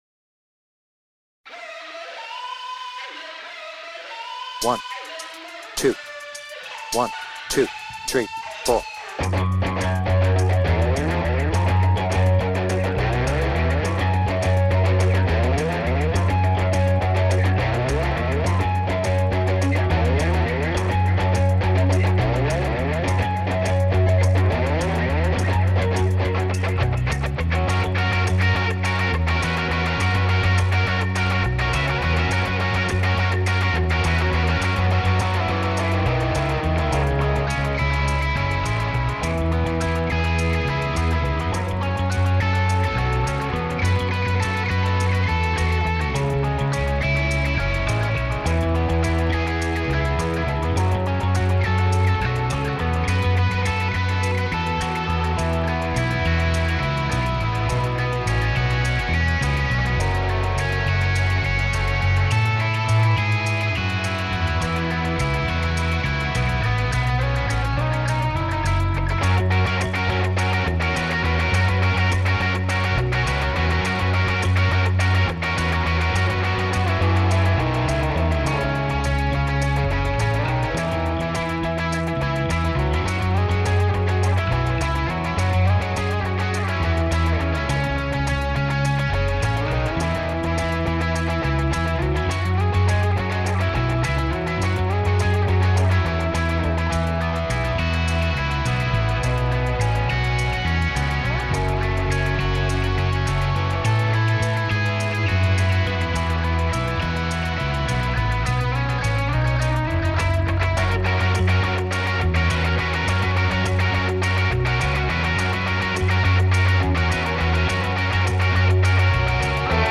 BPM : 104
Without vocals